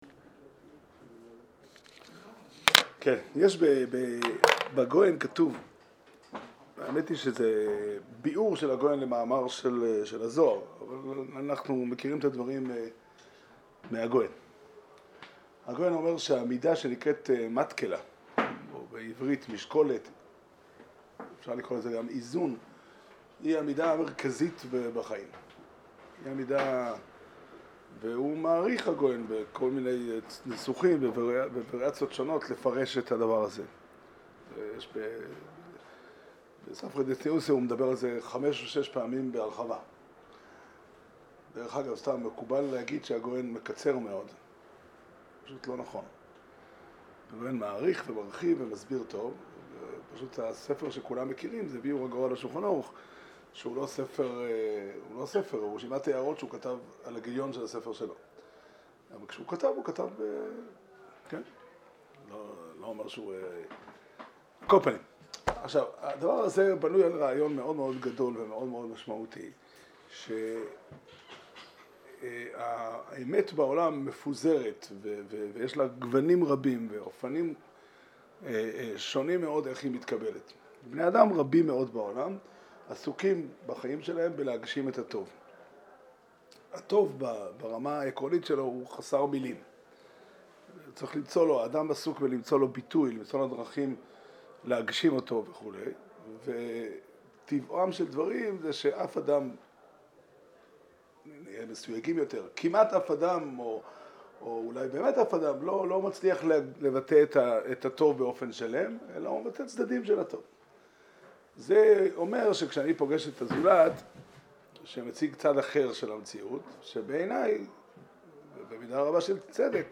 שיעור שנמסר בבית המדרש 'פתחי עולם' בתאריך כ"ג תמוז תשפ"ג